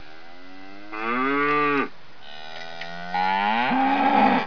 دانلود صدای حیوانات جنگلی 84 از ساعد نیوز با لینک مستقیم و کیفیت بالا
جلوه های صوتی